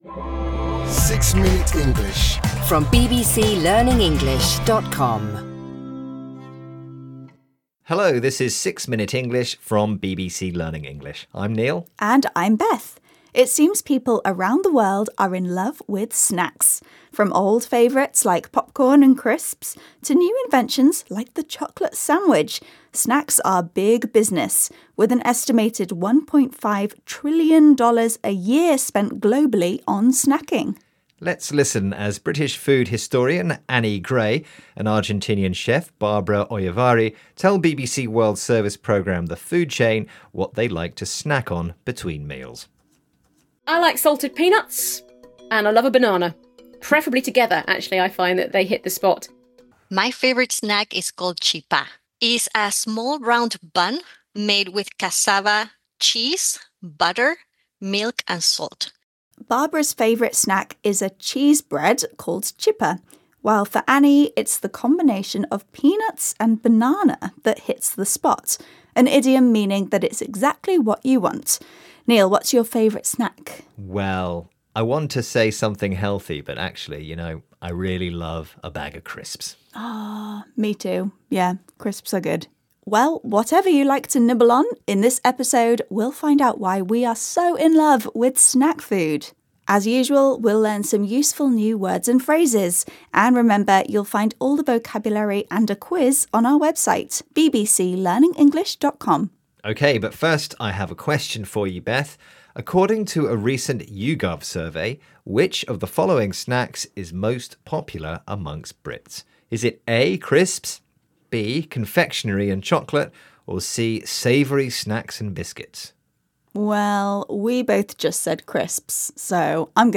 گویندگان این پادکست با لهجه انگلیسی بریتانیایی (British English) صحبت می‌کنند که منبعی ایده‌آل برای افرادی است که قصد شرکت در آزمون آیلتس دارند.
هر قسمت این پادکست شامل گفت‌وگویی کوتاه و جذاب درباره موضوعات متنوعی است که به زبان ساده و قابل‌فهم ارائه می‌شود تا به شنوندگان در تقویت مهارت‌های شنیداری، مکالمه و یادگیری واژگان جدید کمک کند.